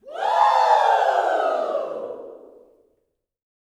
WOO  20.wav